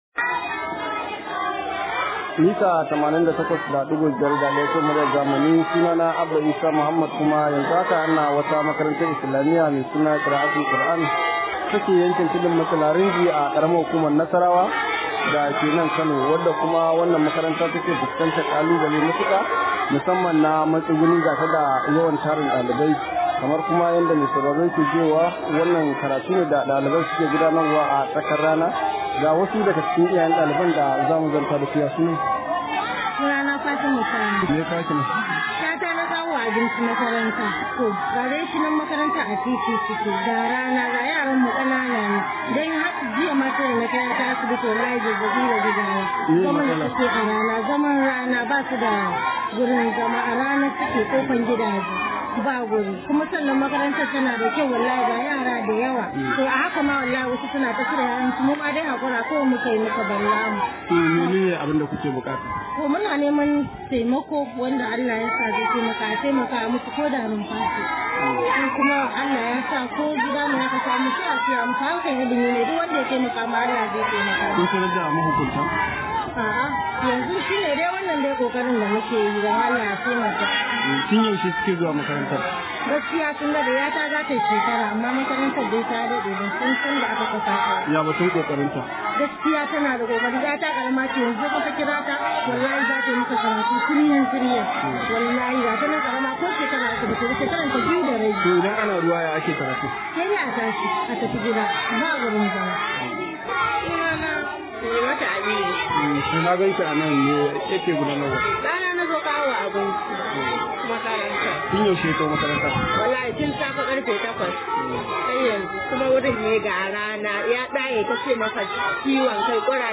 Rahoto: ‘Yayan mu a rana su ke karatu saboda rashin wurin zama – Unguwar Rinji